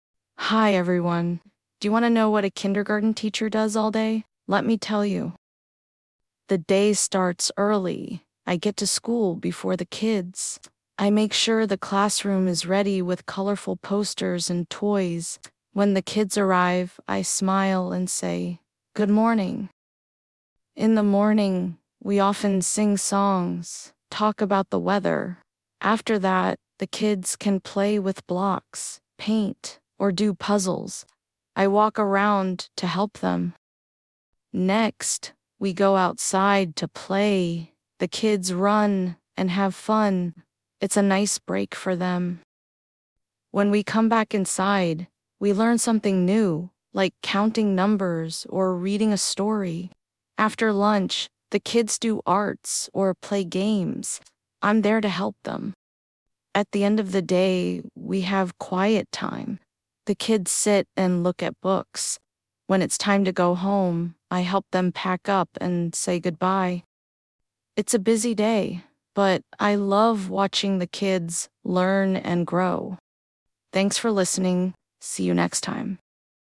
Slow English Podcast for beginners: